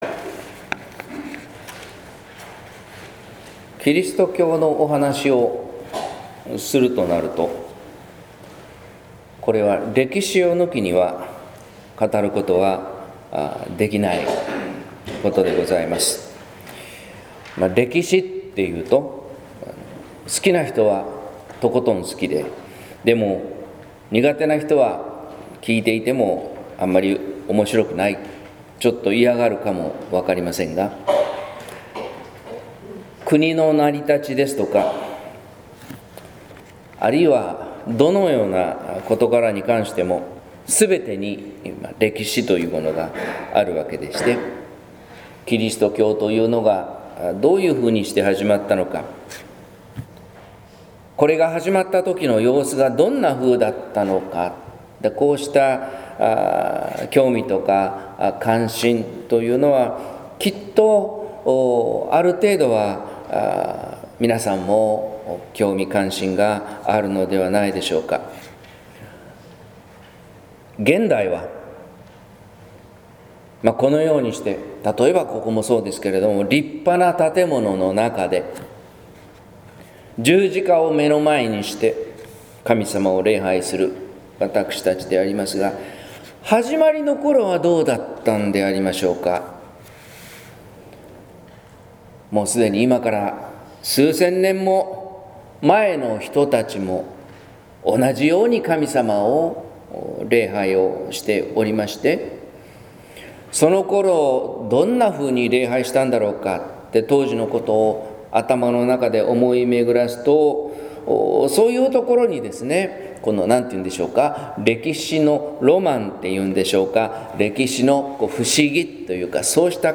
説教「悪霊の正体」（音声版） | 日本福音ルーテル市ヶ谷教会